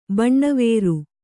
♪ baṇṇavēru